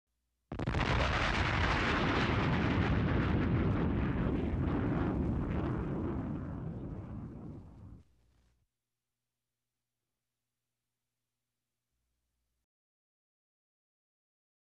TRUENO TORMENTA NOCHE DE TORMENTA
Ambient sound effects
Descargar EFECTO DE SONIDO DE AMBIENTE TRUENO TORMENTA NOCHE DE TORMENTA - Tono móvil
Trueno_Tormenta_NOCHE_DE_TORMENTA.mp3